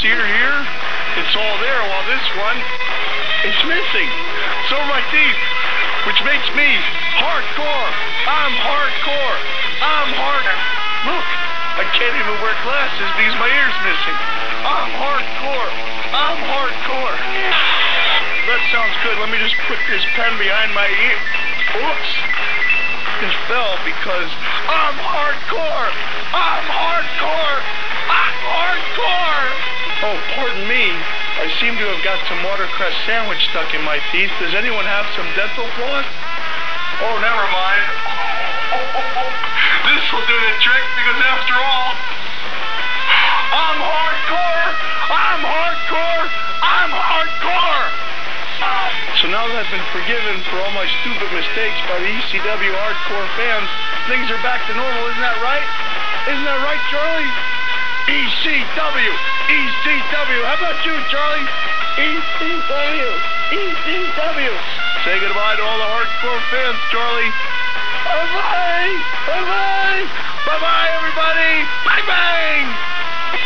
Its prolly the best interview ever in wrestling. Cactus Jack breaks kayfabe and talks about how he wants Tommy Dreamer to stop being hardcore. He then starts going nuts and talks about how great his life is, because he's hardcore.